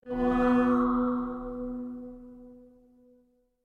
ps3-call.mp3